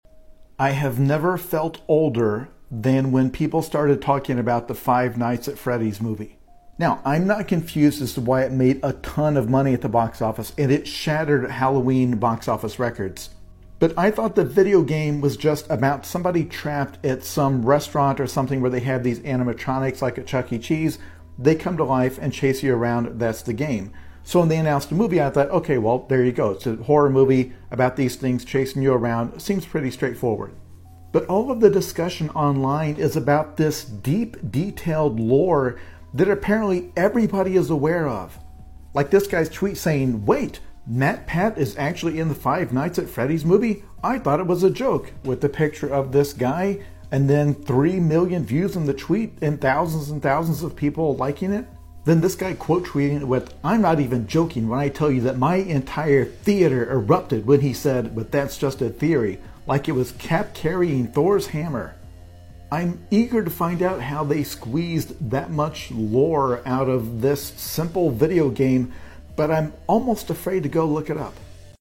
Creepy and noisy synth lead